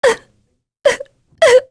Talisha-Vox_Sad_2.wav